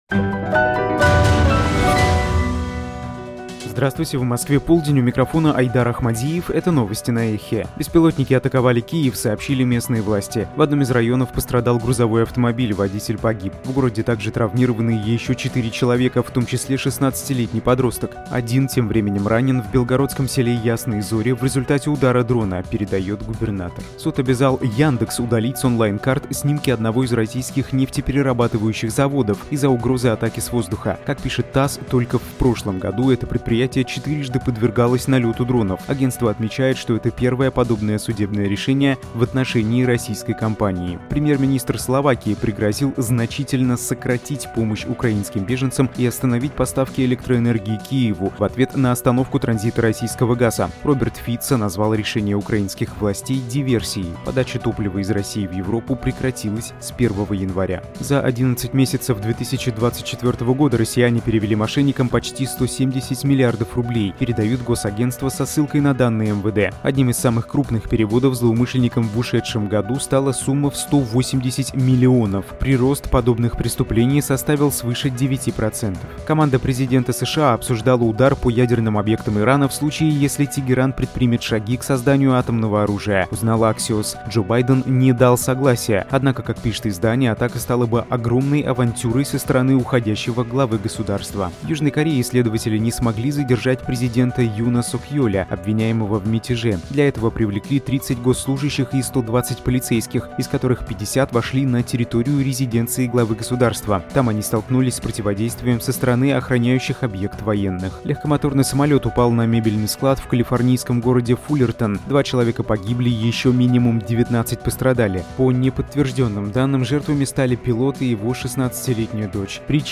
Новости